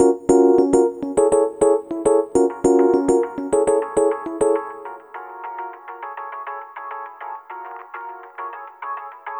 Ala Brzl 1 Fnky Piano-D#.wav